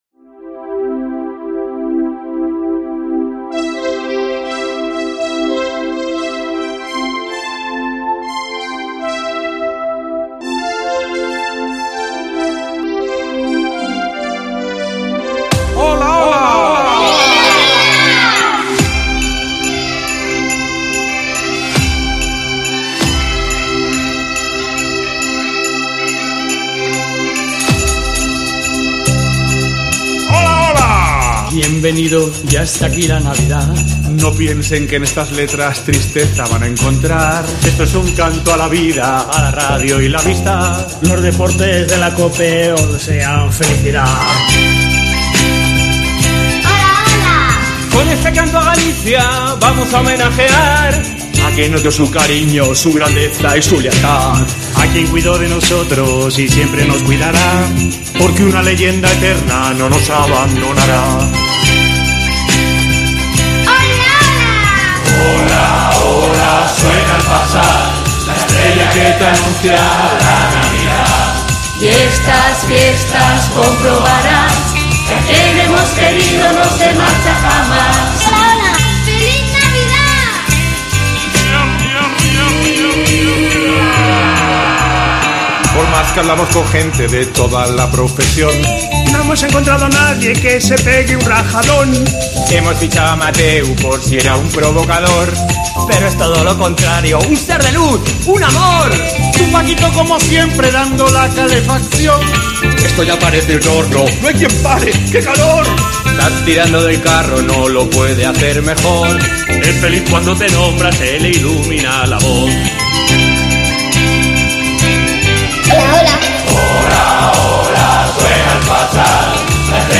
Llega ya la Navidad y como es tradición la redacción de Deportes COPE da la bienvenida a las fiestas con el tradicional villancico.
VILLANCICO NAVIDAD 2023 DEPORTES COPE